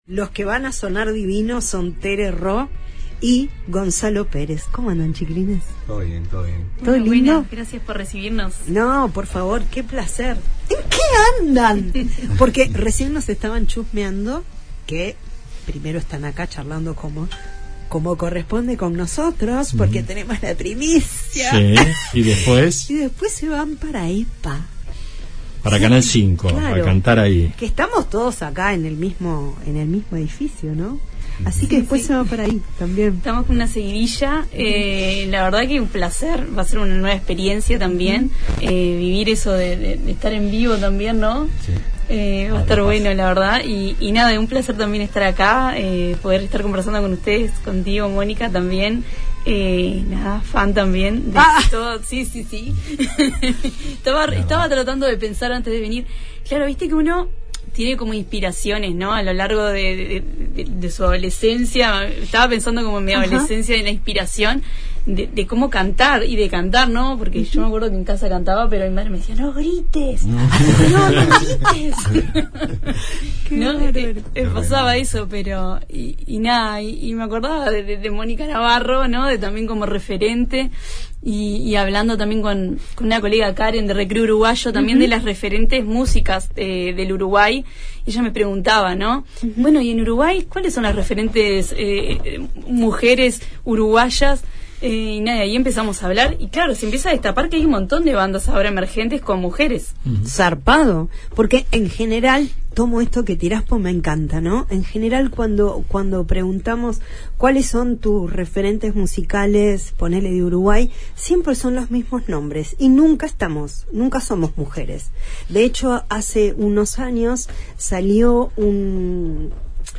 voz
guitarra
para interpretar en vivo uno de sus nuevos lanzamientos